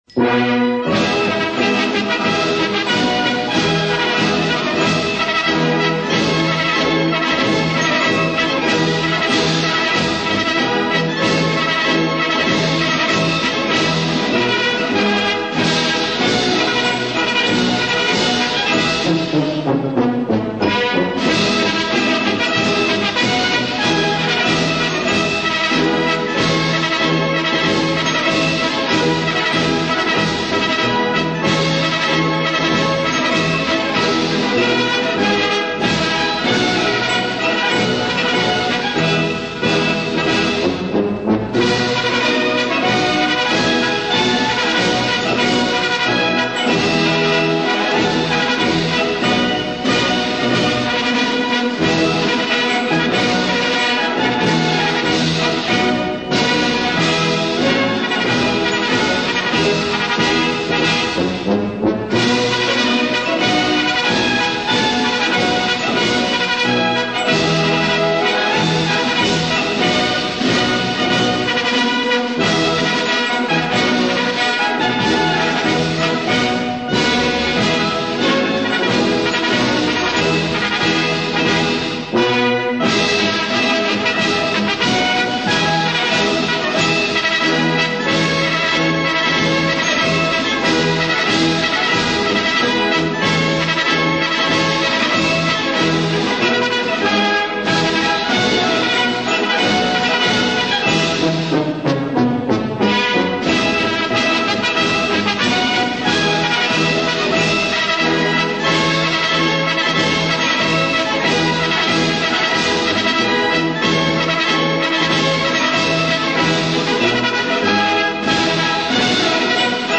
Marschmusik